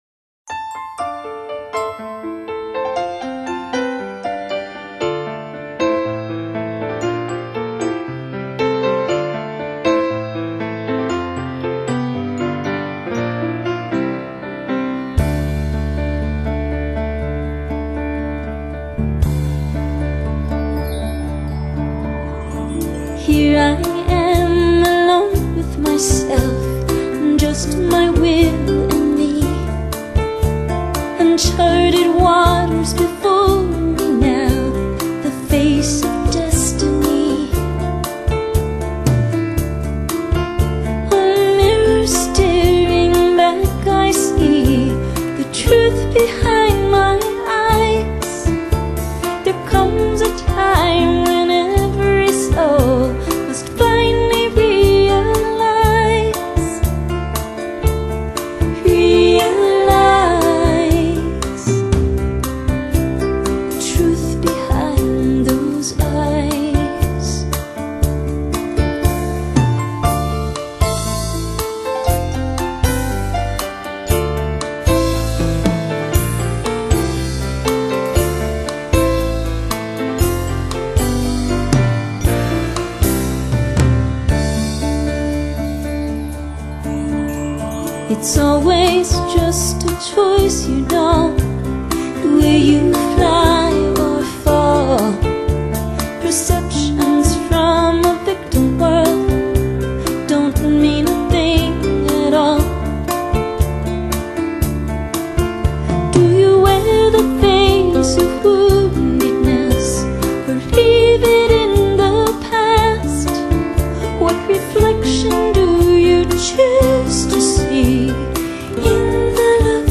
专辑类型：凯尔特音乐
流行的音场的ECHO，或者添加电子合成器的渲染，就是靠着简洁而优美的旋律，甜美